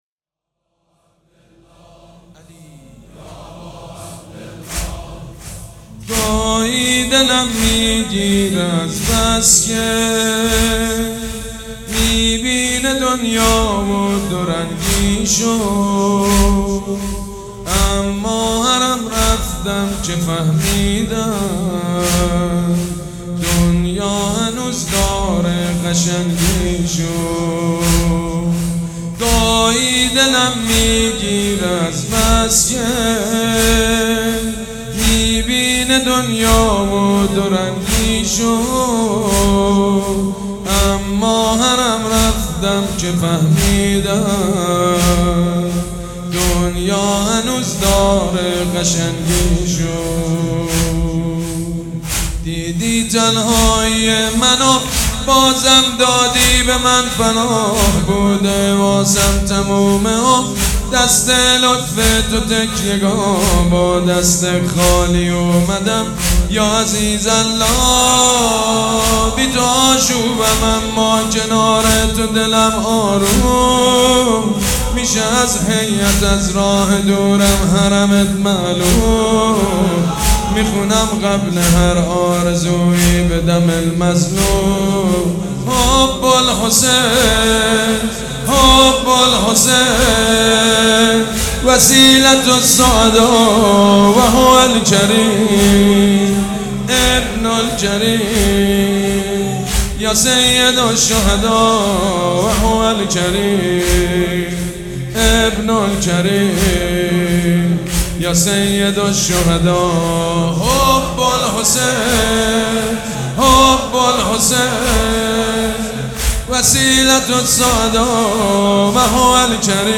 مراسم عزاداری شب سوم